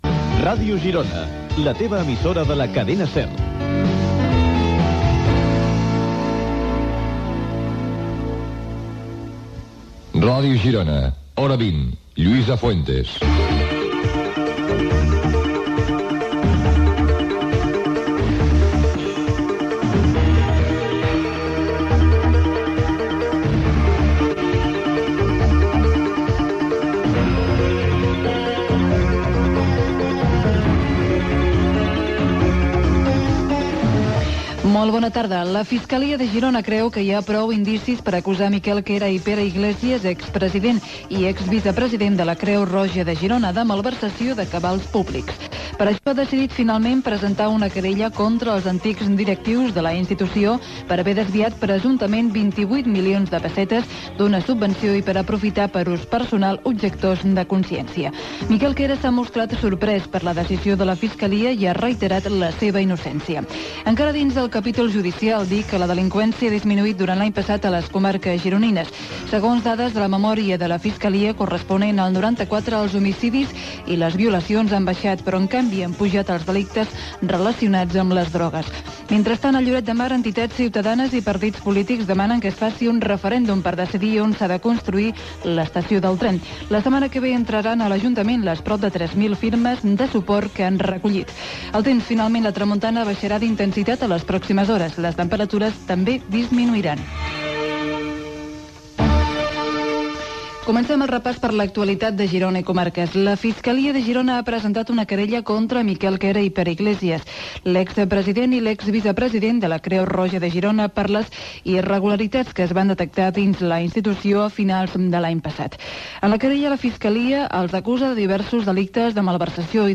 Indicatius de l'emissora i del programa, resum informatiu, acusats de malversació a antics directius de la Creu Roja
Informatiu